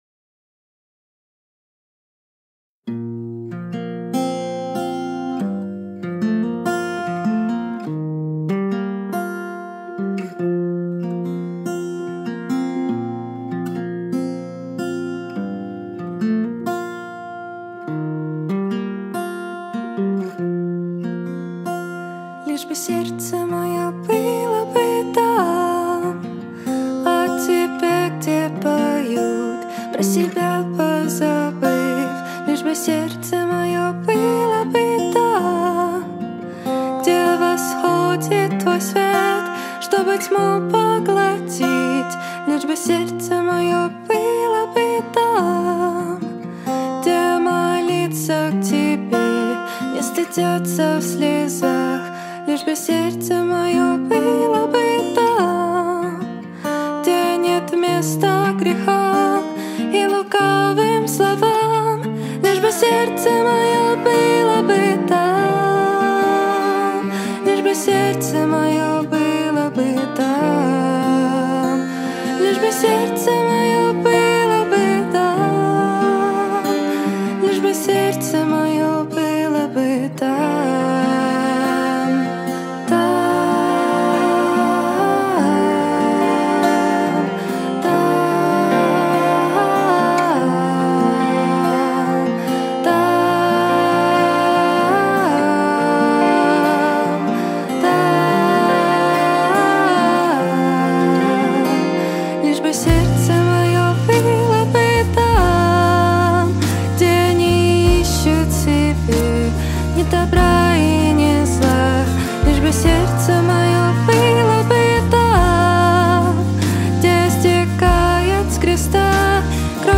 87 просмотров 85 прослушиваний 4 скачивания BPM: 144